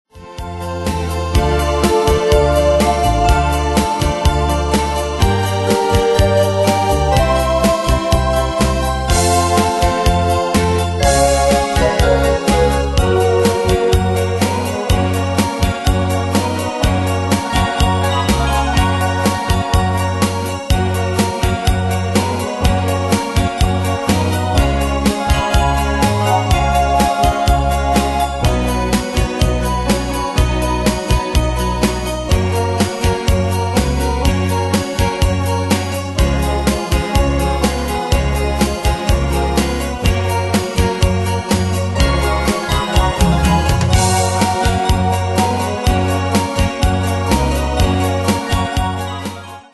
Danse/Dance: Continental Cat Id.
Pro Backing Tracks